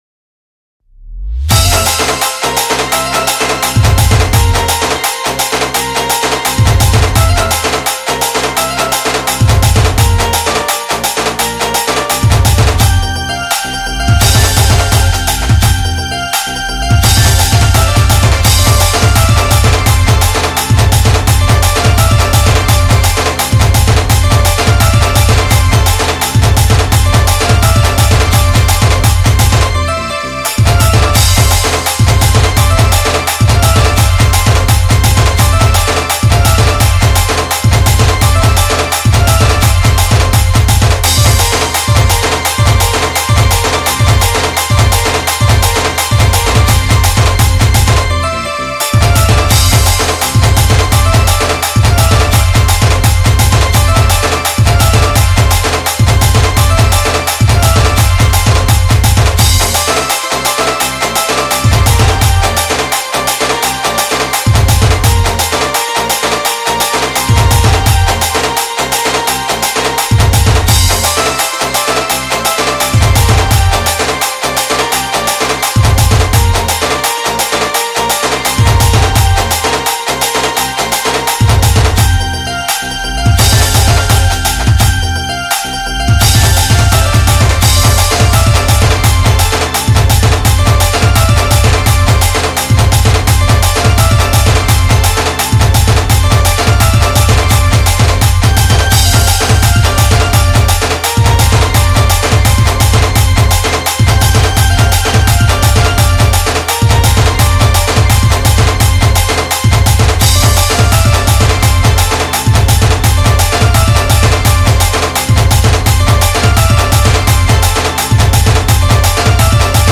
Instrumental Music And Rhythm Track